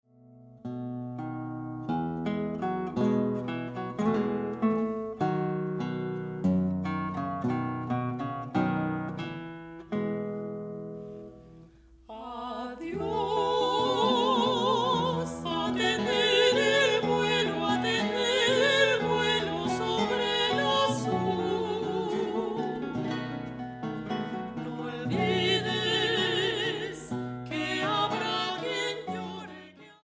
Grabado en la Sala Julián Carrillo de Radio UNAM